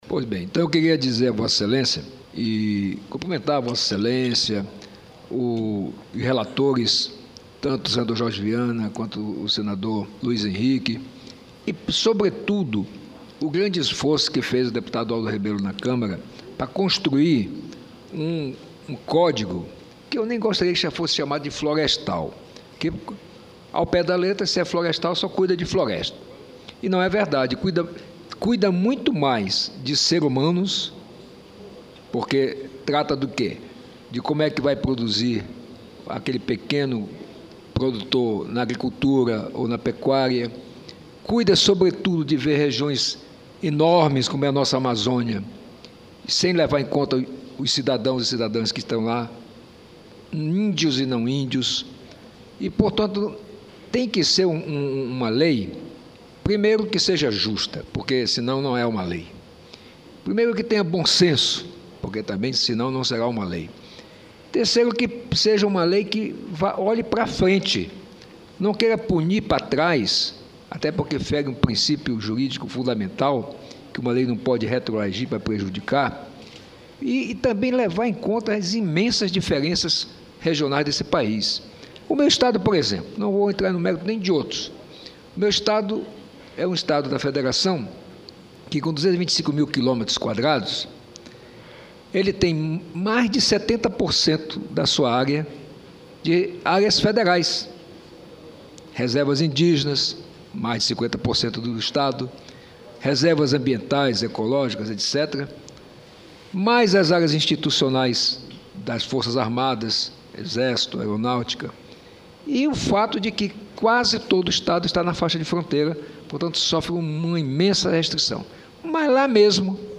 Discuso do senador Blairo Maggi (2)